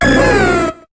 Cri de Celebi dans Pokémon HOME.